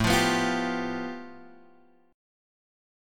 A7sus2#5 chord {5 x 3 4 6 3} chord